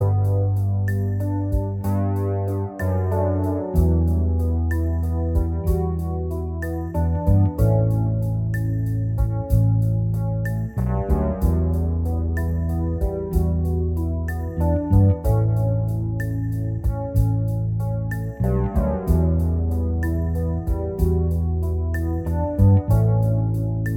Minus Guitars Indie / Alternative 5:20 Buy £1.50